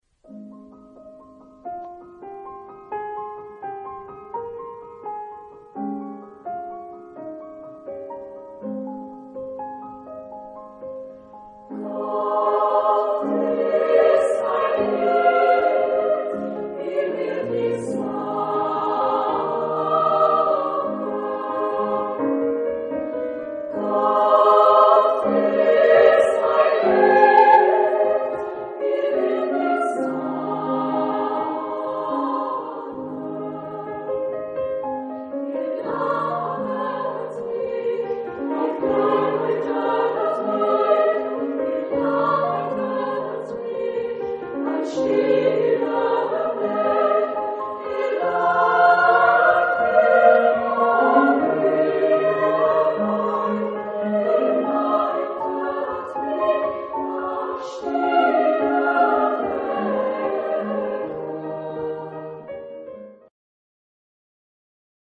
Type de choeur : égales de femmes